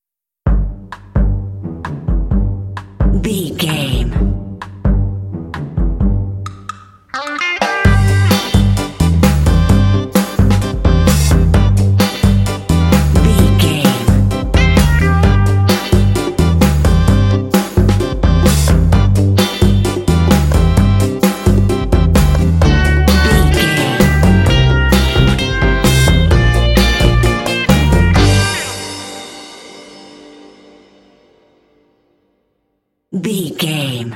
Uplifting
Aeolian/Minor
funky
groovy
driving
drums
brass
electric guitar
bass guitar
organ
conga
Funk